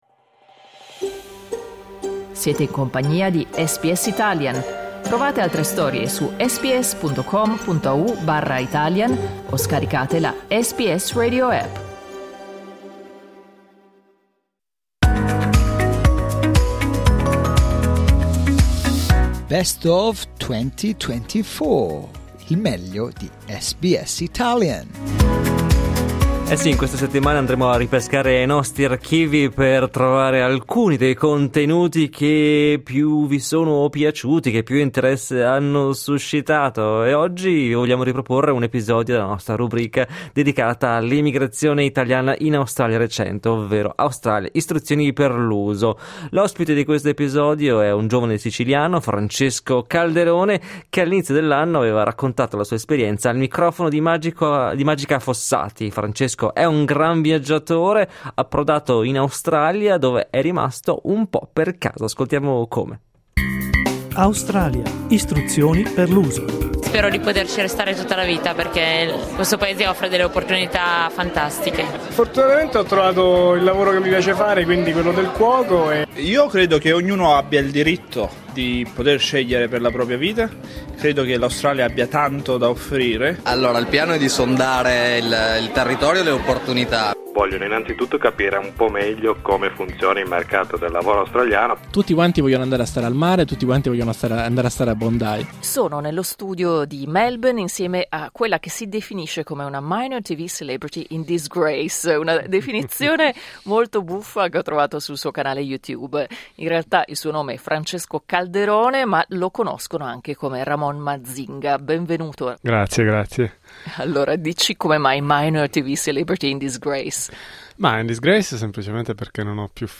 Clicca sul tasto "play" in alto per ascoltare l'intervista LEGGI L'ARTICOLO ORIGINALE (PUBBLICATO A MARZO 2024) ED ASCOLTA L'INTERVISTA INTEGRALE L'Australia?